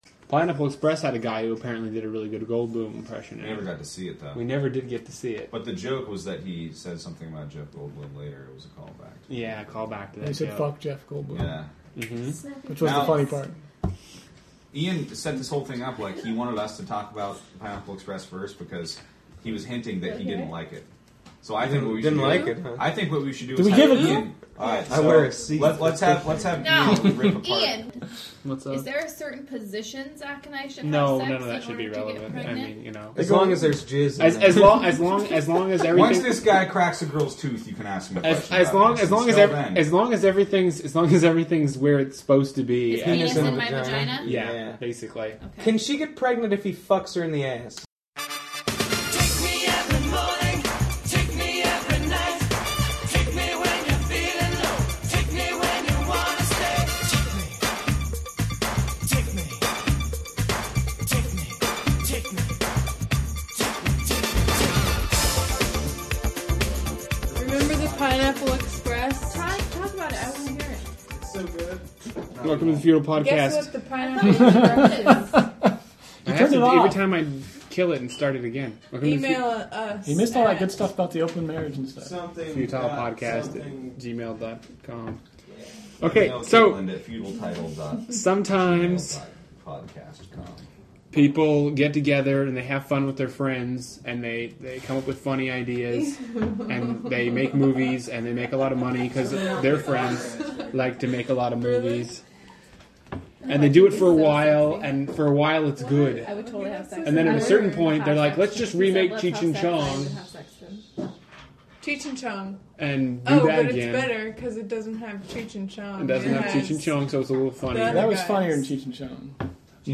We talk about this new Rogen/Apatow/Green joint while the women talk about shoes and boobs.